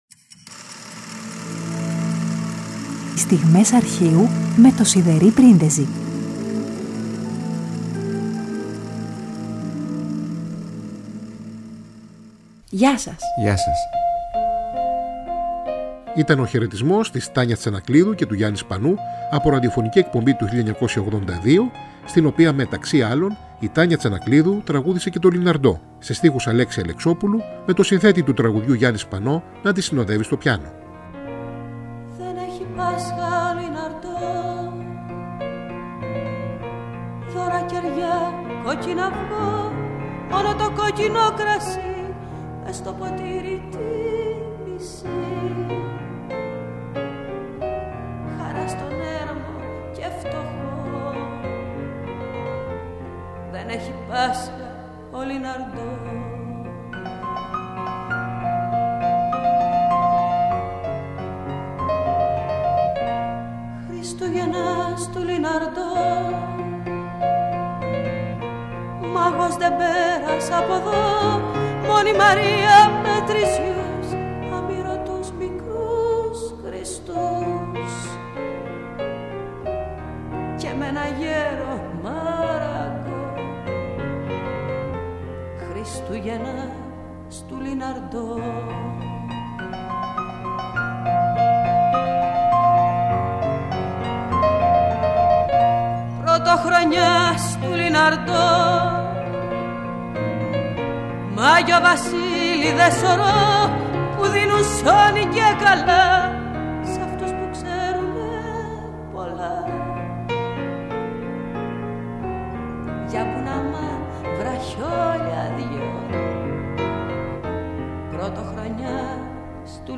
από ραδιοφωνική ηχογράφηση του 1982
με τον συνθέτη να τη συνοδεύει στο πιάνο.